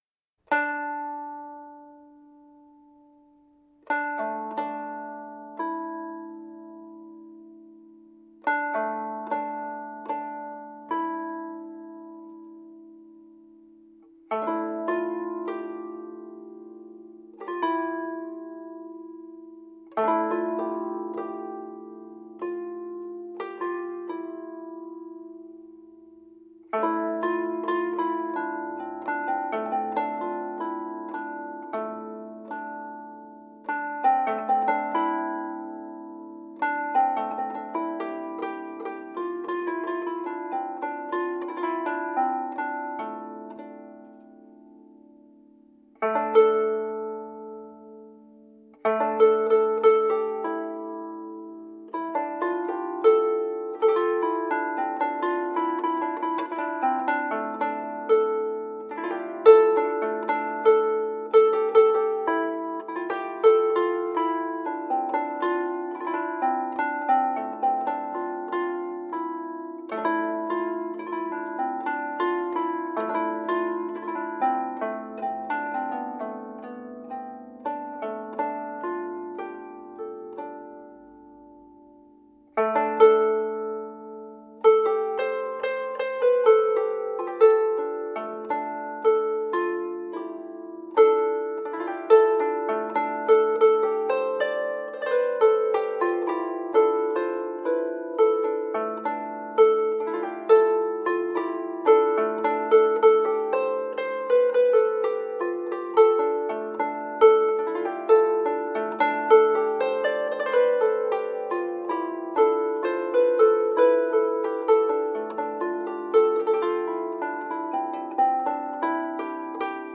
beautiful melodies played on historic instruments
World, Medieval, Arabic influenced, Harp
Medieval and middle eastern music